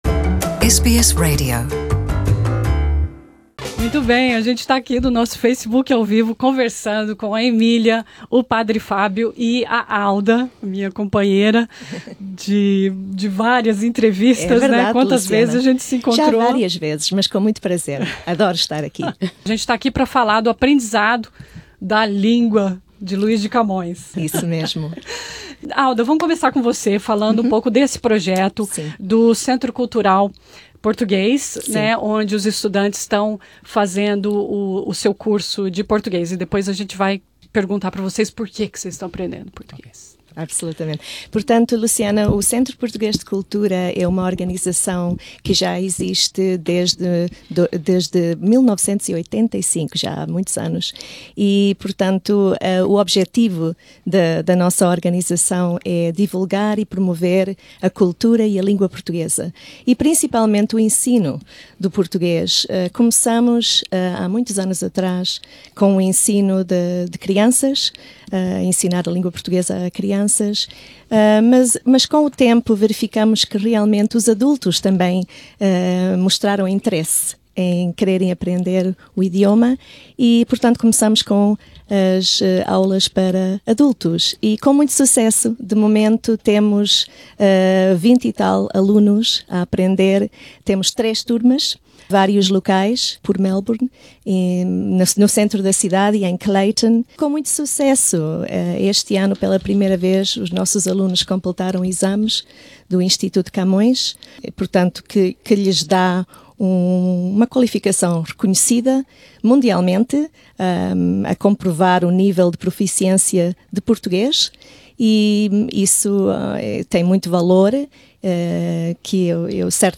Os estudantes de português na Austrália falam porque decidiram aprender o idioma, seus hábitos de estudo e da importância de aprender uma nova língua (qualquer que seja ela).